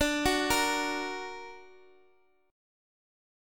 D-Augmented-D-x,x,x,7,7,6-8-down-Guitar-Standard-1.m4a